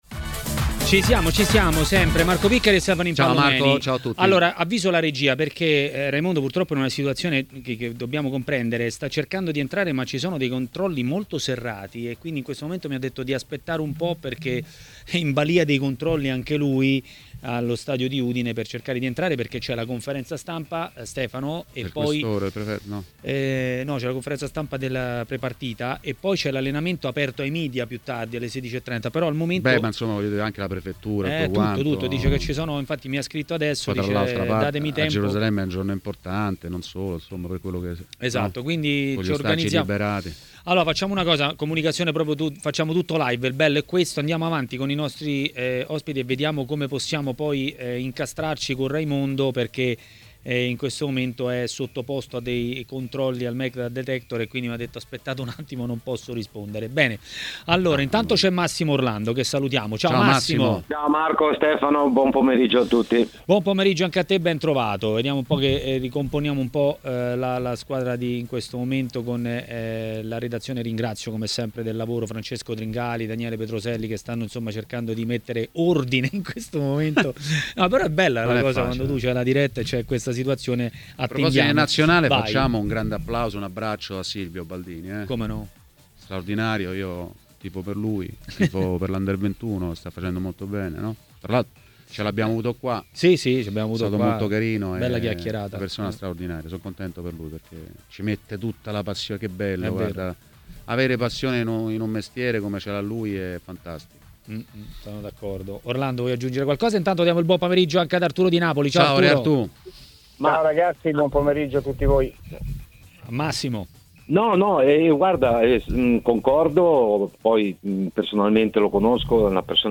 L'ex calciatore Arturo Di Napoli è stato ospite di TMW Radio, durante Maracanà.